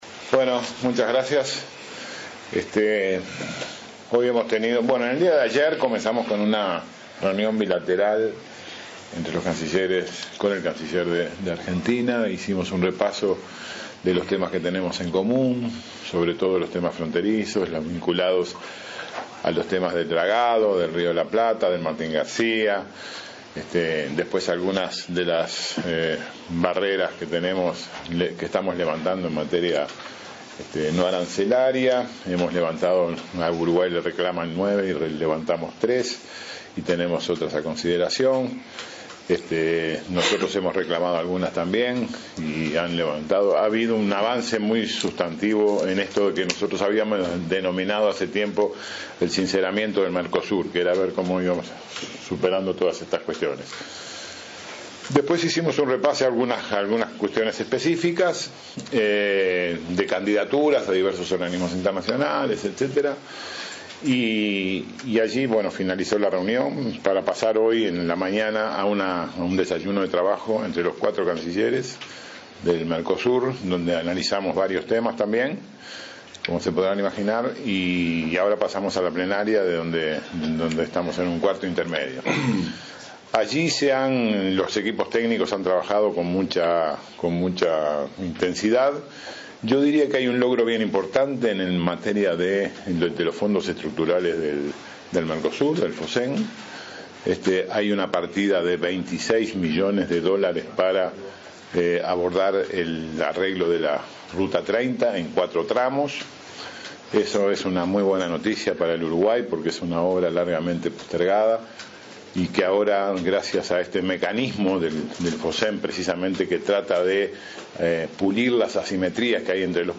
conferencia.mp3